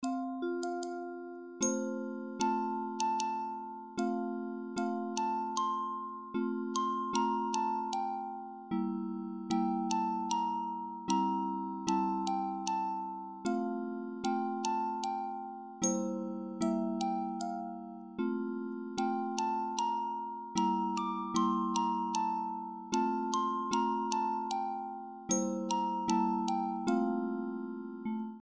オルゴールアレンジは、美しいメロディーが雪山の静謐な雰囲気と調和し、聴く者を心地よい空間へと誘います。
このアレンジでは、オルゴール特有の優雅で穏やかな響きに変えています。
民謡